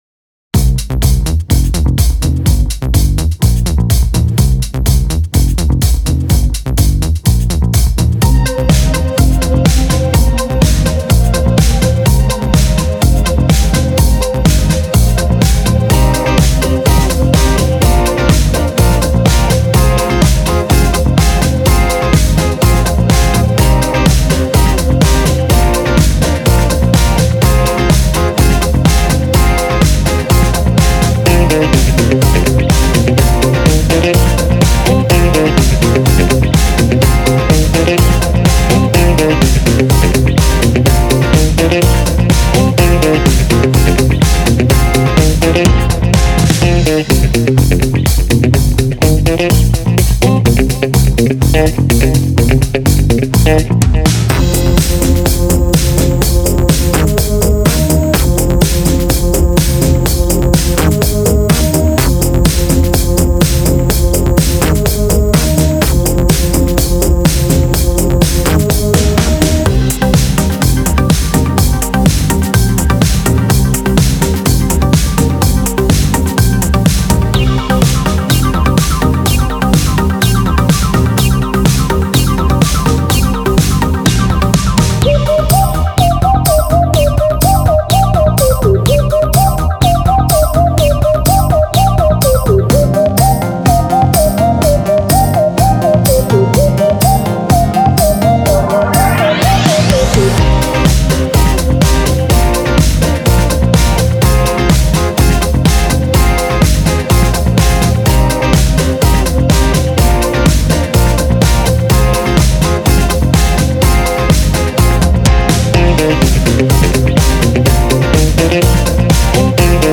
# Dance   # RnB    # Electronic    # Alternative Rock